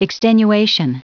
Prononciation du mot extenuation en anglais (fichier audio)